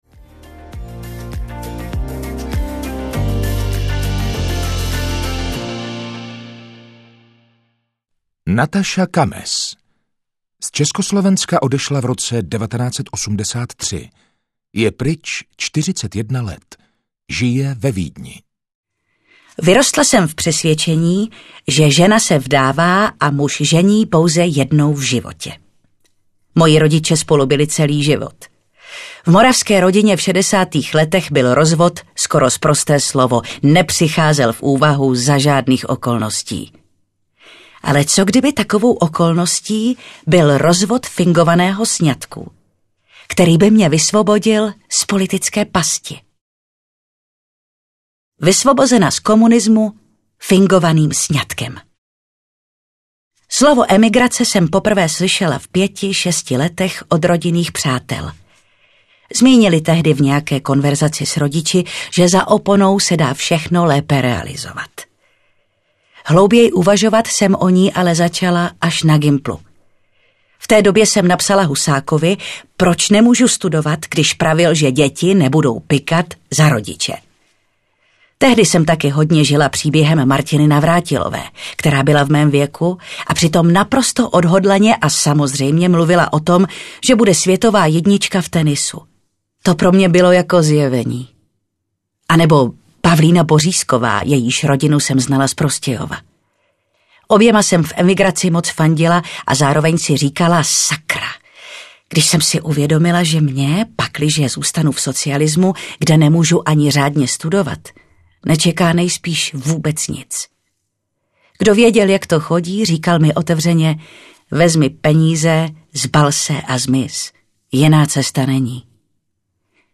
České cizinky audiokniha
Ukázka z knihy